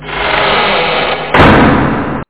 OpenCloseOldDoor.mp3